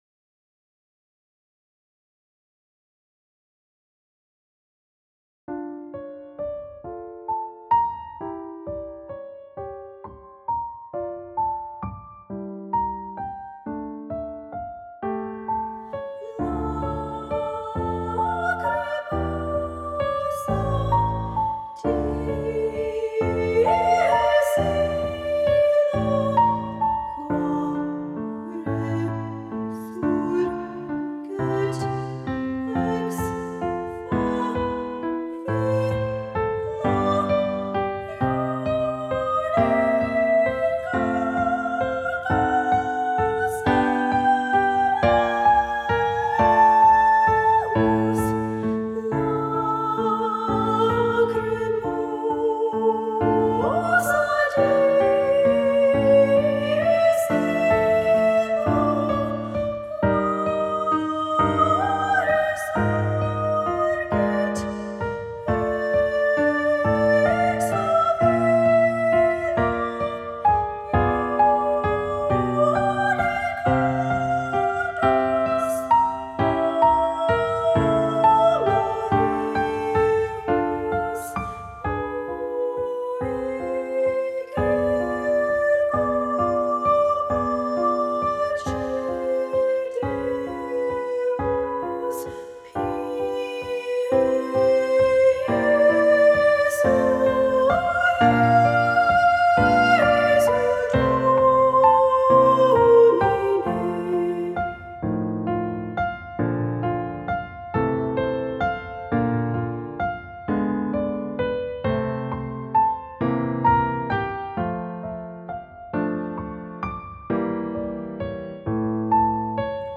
Attached are practice tracks for the Lacrimosa.